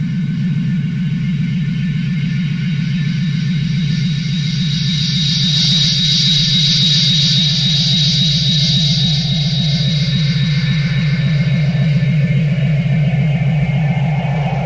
ヒップホップ＆ラテン/サルサ収録のオムニバス！